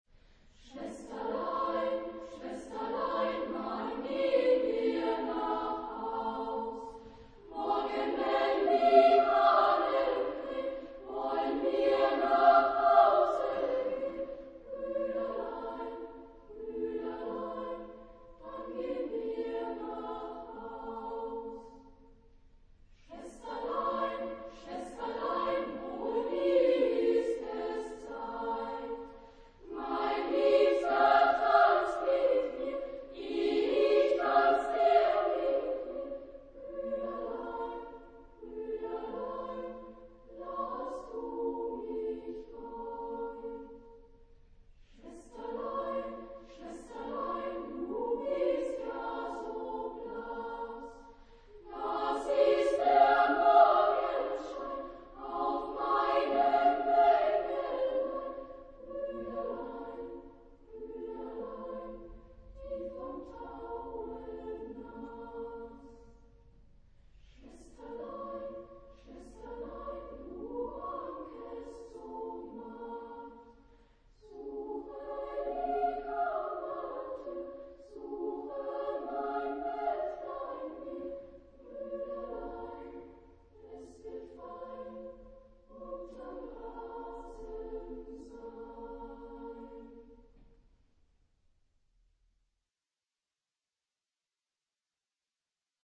Genre-Style-Forme : Chanson ; Profane
Type de choeur : SSA  (3 voix égales de femmes )
Tonalité : la mineur
Réf. discographique : 3. Deutscher Chorwettbewerb 1990 Stuttgart